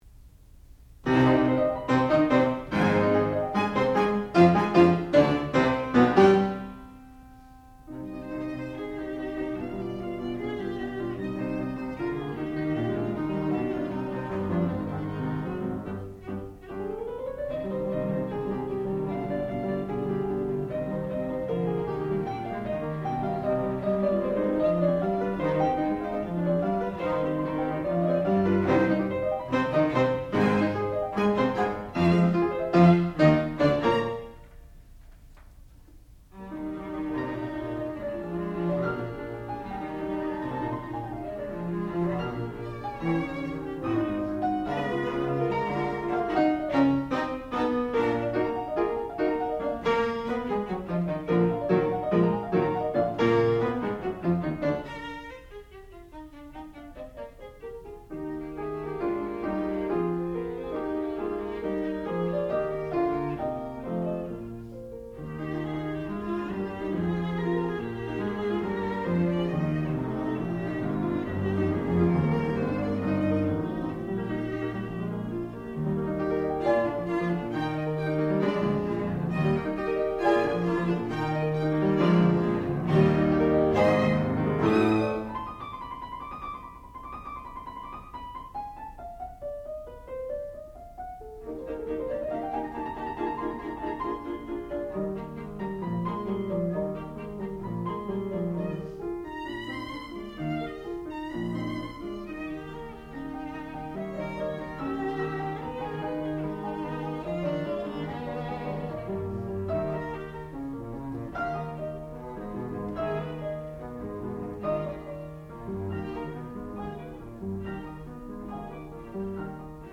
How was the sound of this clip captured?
Master's Degree Recital